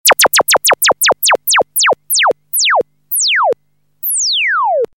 laser-beam-gun_25071.mp3